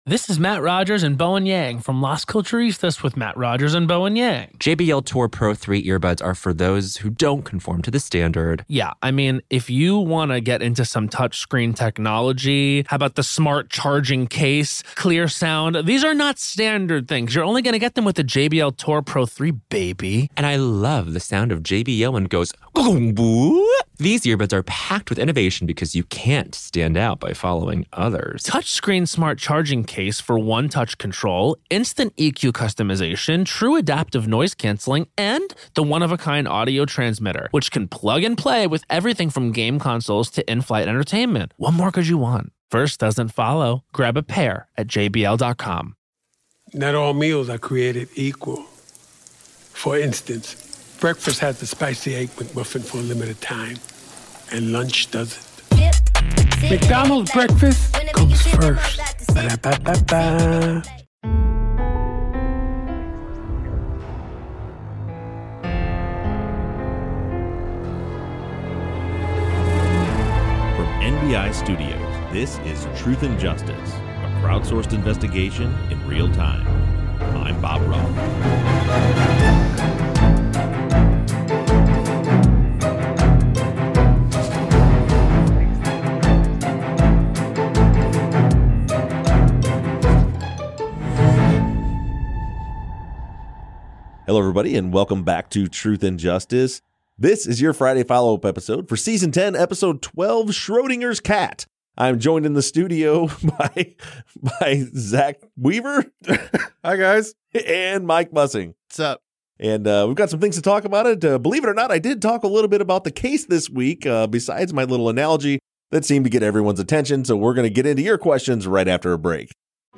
The guys discuss listener questions from social media